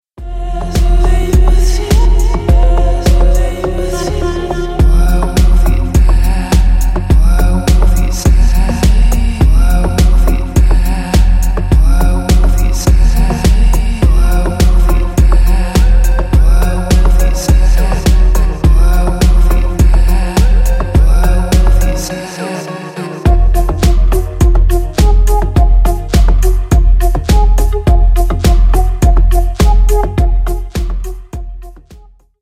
Клубные Рингтоны » # Громкие Рингтоны С Басами
Танцевальные Рингтоны